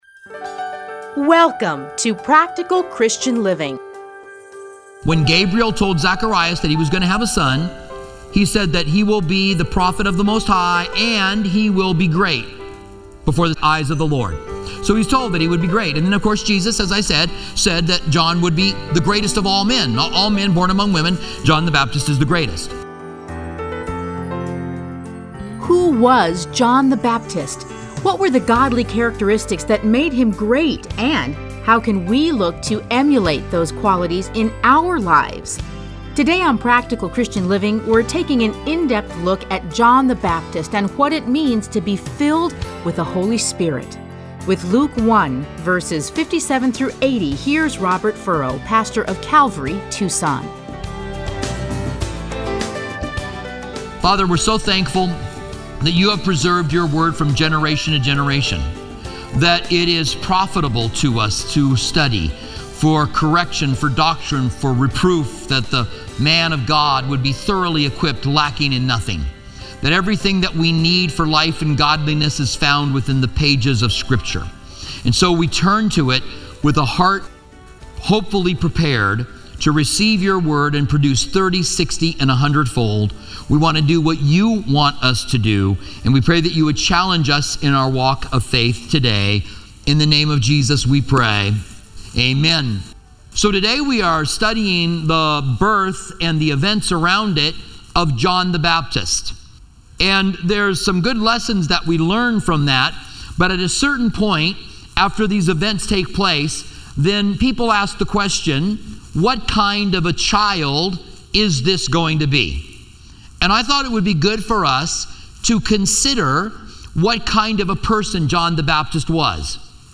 Listen to a teaching from Luke 1:57-80.